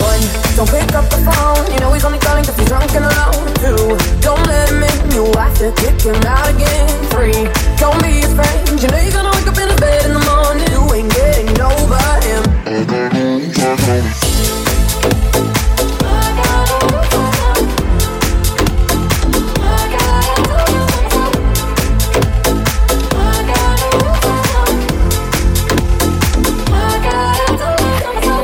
Genere: pop, house, deep, club, edm, remix